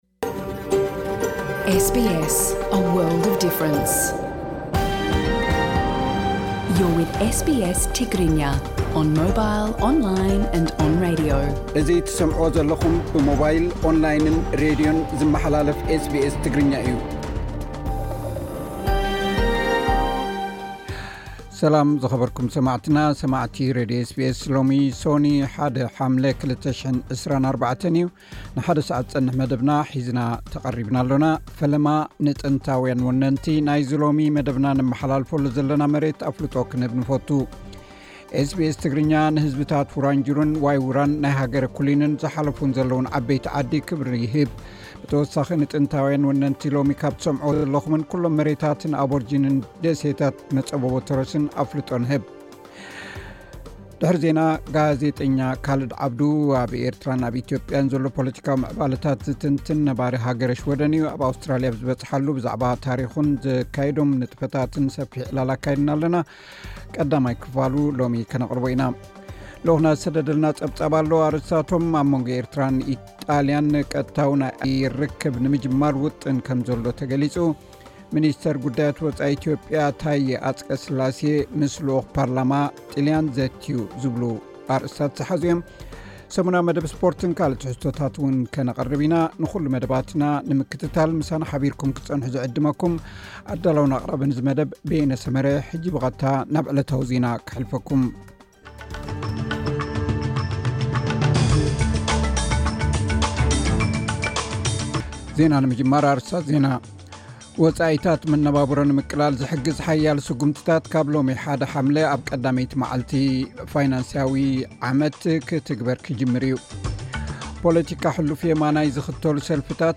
ኣብ ኣውስትራሊያ ኣብ ዝበጽሓሉ ብዛዕባ ታሪኹን ዘካይዶም ንጥፈታትን ሰፊሕ ዕላል ኣካይድናሉ ኣለና።